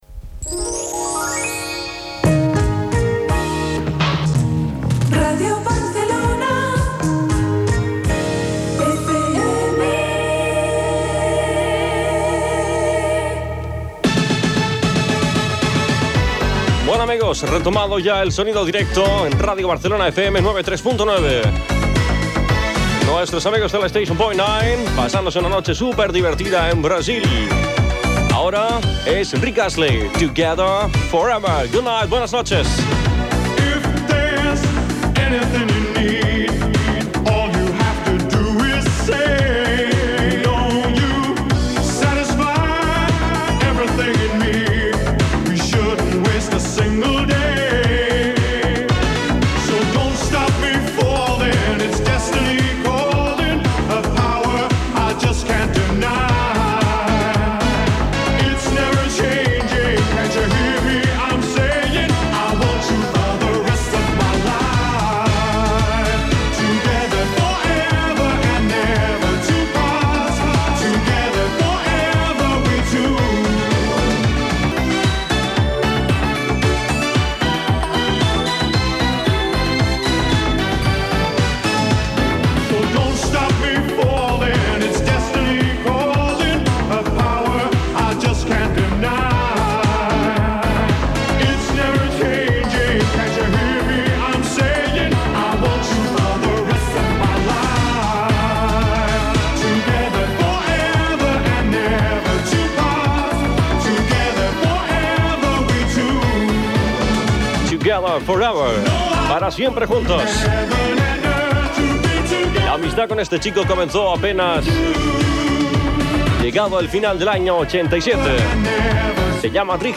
Indicatiu de l'emissora, presentació d'un tema musical, indicatiu de Los 40 Principales i presentació d'un nou tema.
Musical